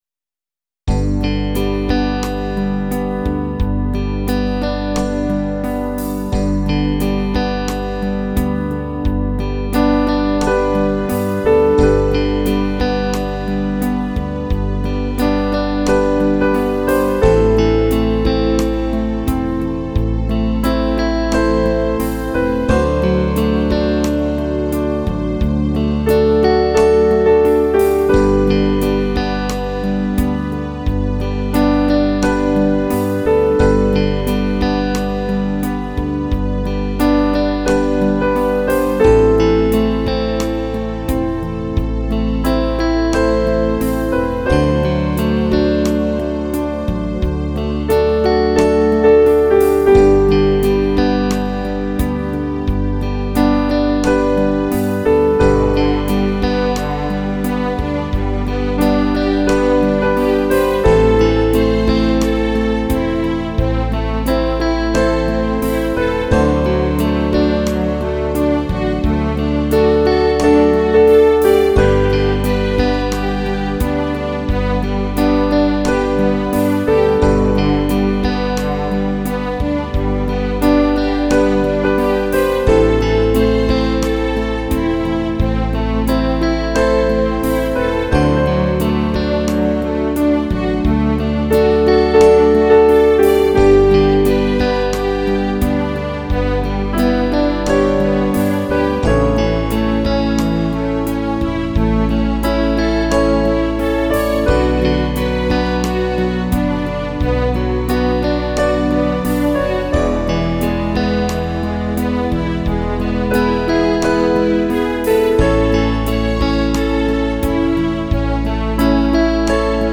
T. + M.: Roland Patzleiner
Instrumentalaufnahme